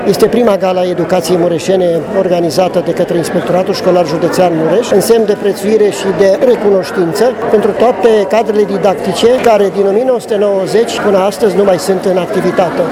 Prima ediție a Galei Educației Mureșene a avut loc astăzi la Palatul Culturii și a fost dedicată dascălilor pensionari, care au primi diplome și medalii.